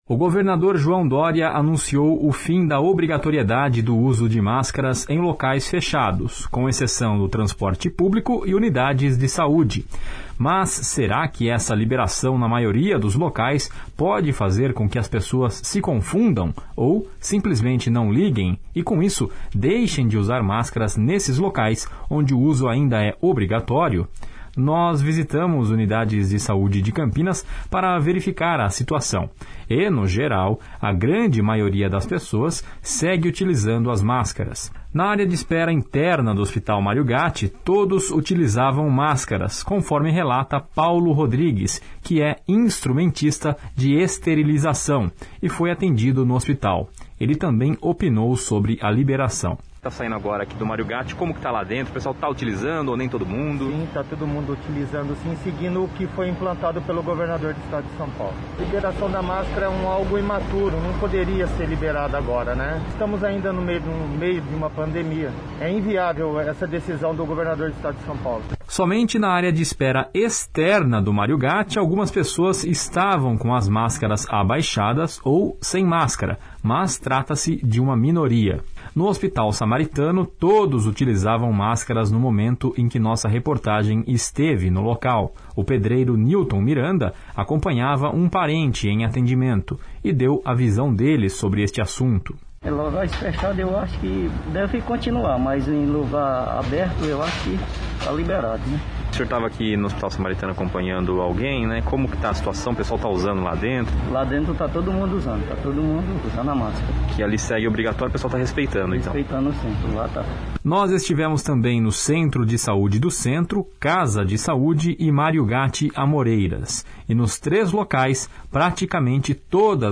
Nós visitamos unidades de saúde de Campinas para verificar a situação, e, no geral, a grande maioria das pessoas segue utilizando as máscaras.